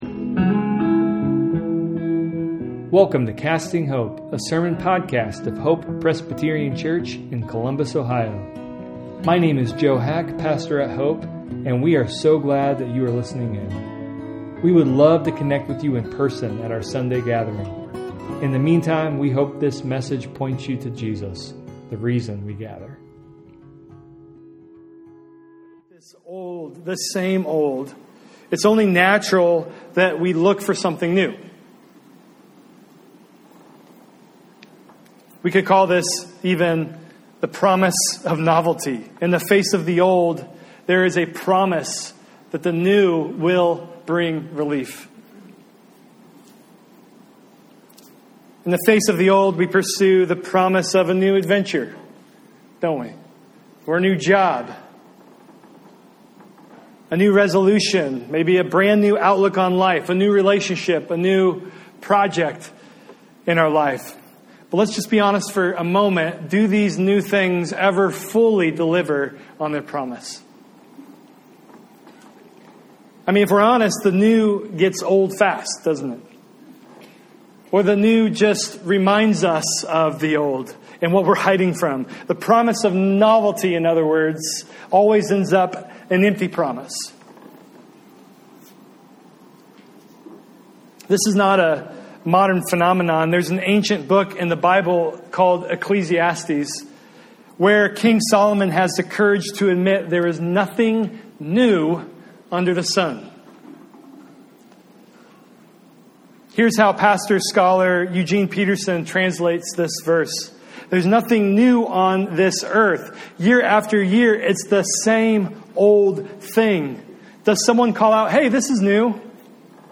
easter-sermon-2025.mp3